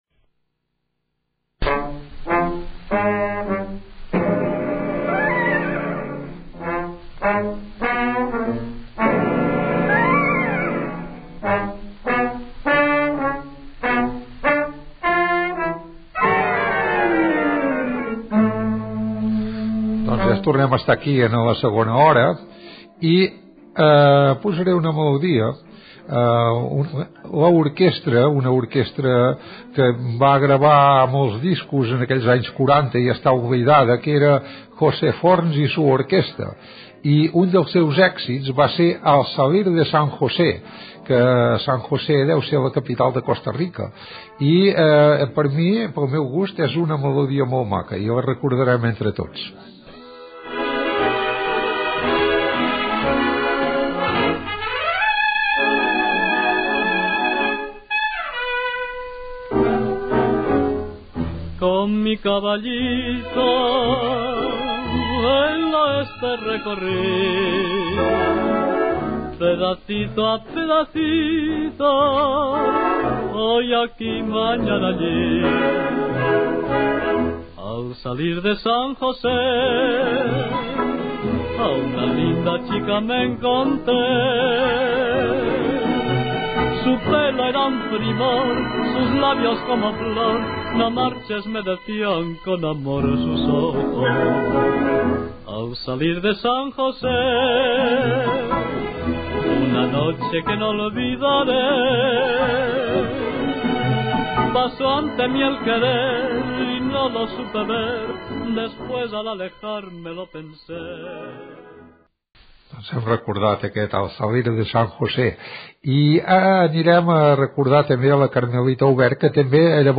Inici de la segona hora, tema musical, cançó de la colònia Varón Dandy, reposició d'un programa sobre la censura Gènere radiofònic Entreteniment